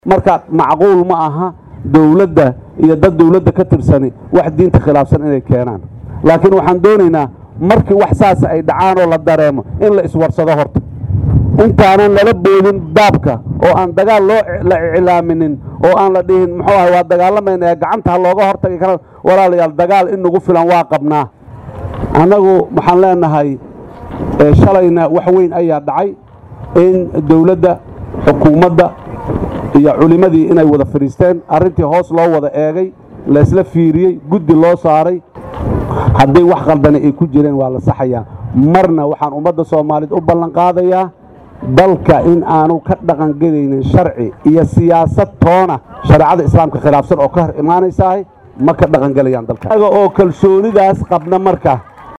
Madaxweynaha Dowlada Federaalka Soomaaliya Xasan Sheekh Maxamuud ayaa ka hadlay Eedeynta Hay’ada culimada Soomaaliyeed ay u jeediyeen Xukuumada ee ku aadan sharciga Siyaasada Haweenka oo ay meel mariyeen golaha Wasiirada Soomaaliya.